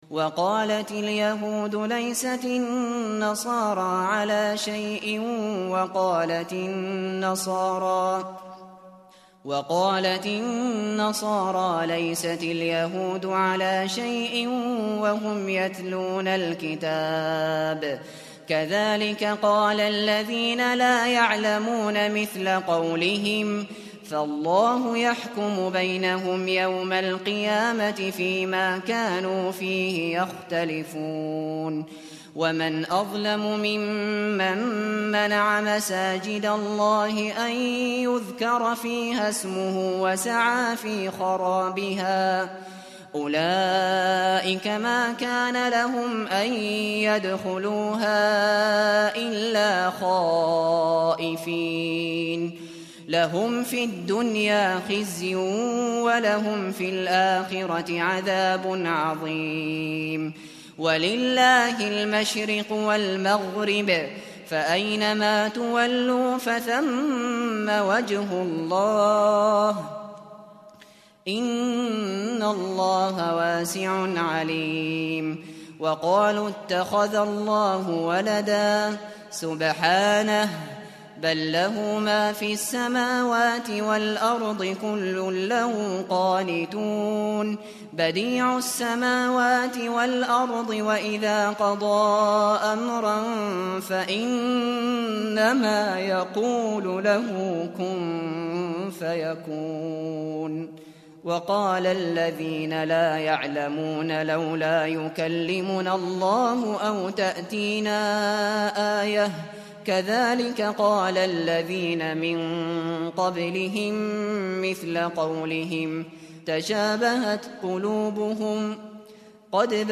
Hafız Abu Bakr al Shatri sesinden Cüz-1, Sayfa-18 dinle!
Hafız Maher Al Mueaqly sesinden Cüz-1, Sayfa-18 dinle!
Hafız Mishary AlAfasy sesinden Cüz-1, Sayfa-18 dinle!